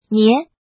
怎么读
nié